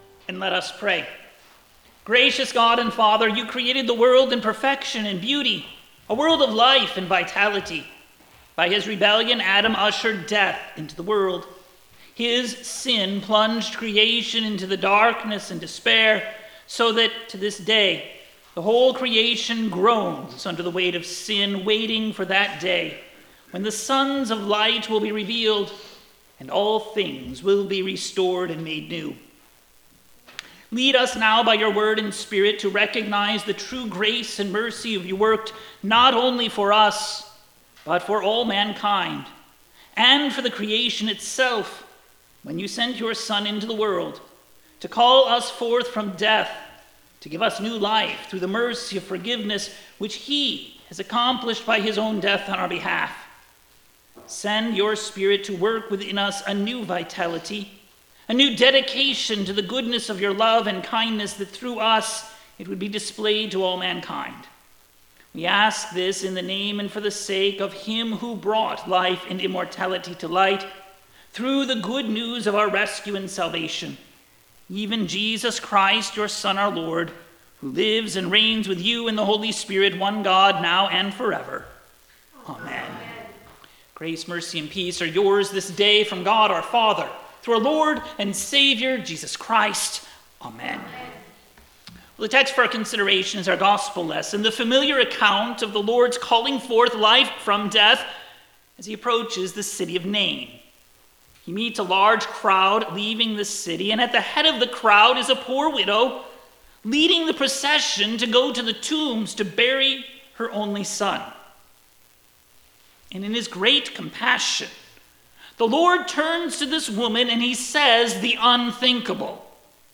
2025-10-05-Sermon.orig.mp3